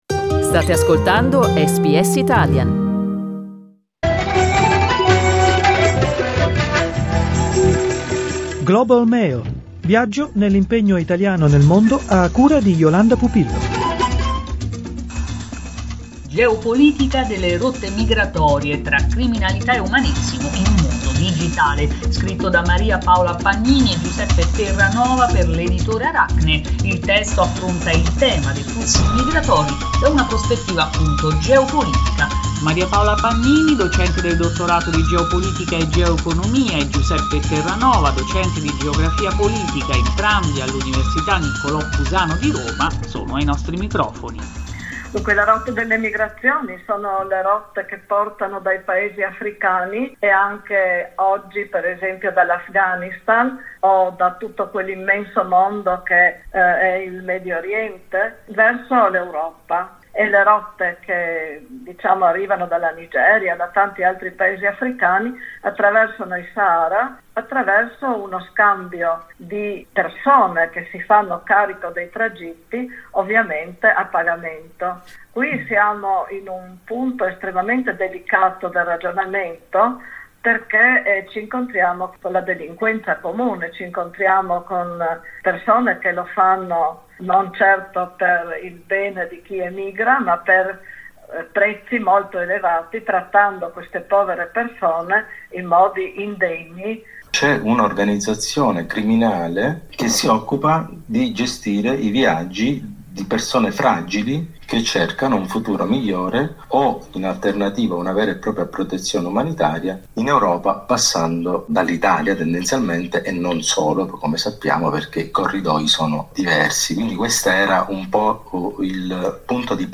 un'intervista con SBS Italian